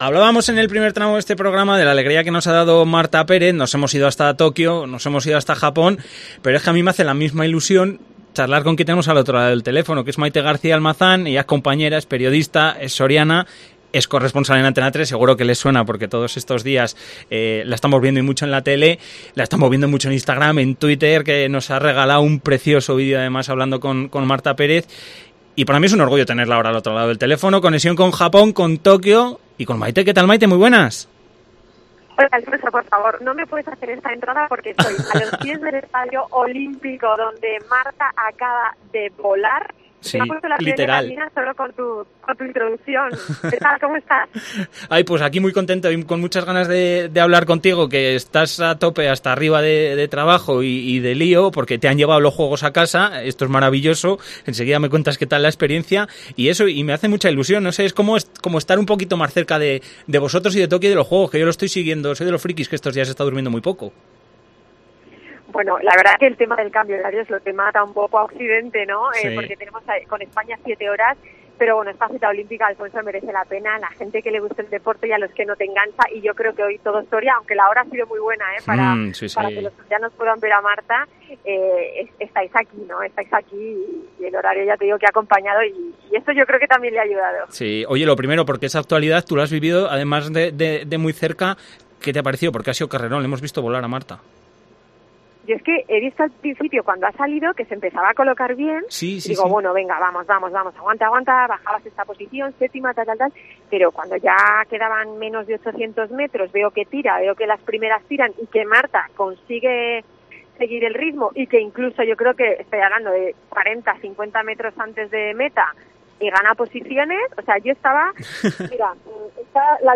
Entrevista.